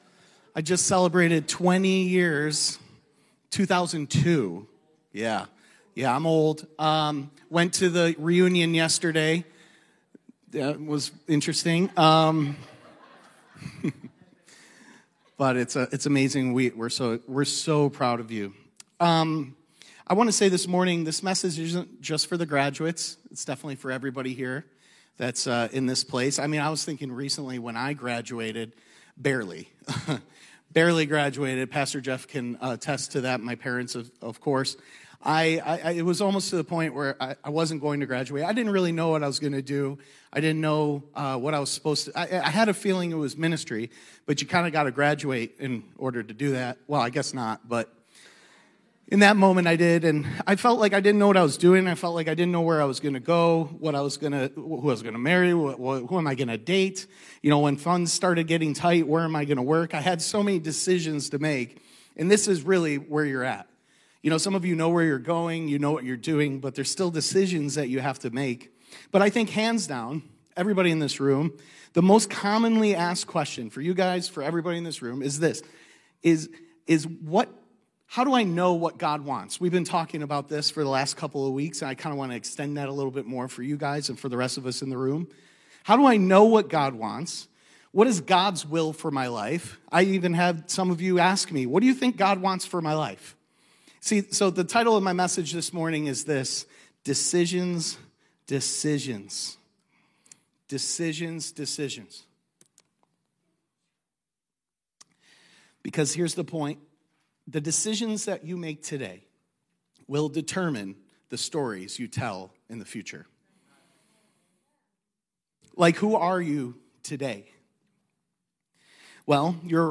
This message was given on our annual Graduate Sunday but this is a great message for anyone who’s wanting to honor God in the decisions of life.